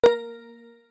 click-short-confirm.ogg